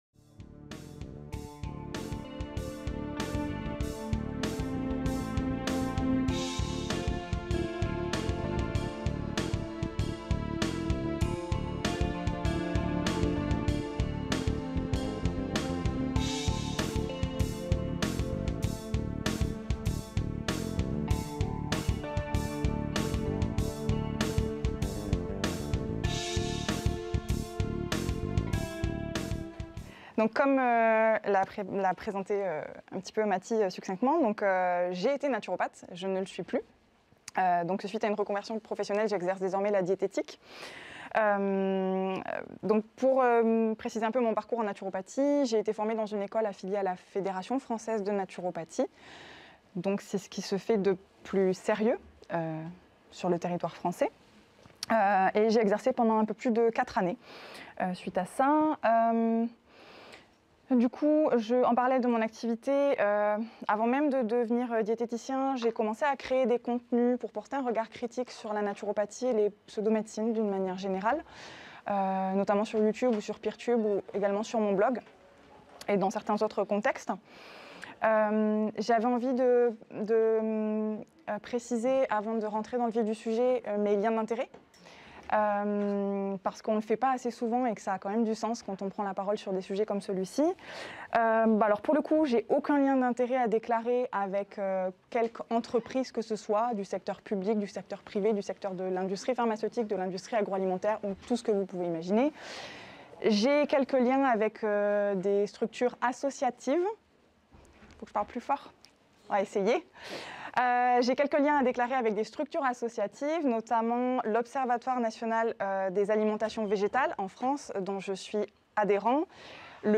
Skeptics in the Pub Bruxelles est un cycle de conférences mensuelles organisé par le Comité Para asbl.